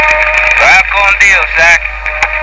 Jimmy à Ike, avec la vraie voix de Josh Brolin !